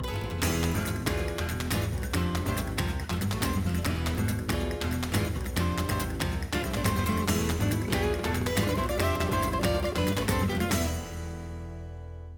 Ripped from game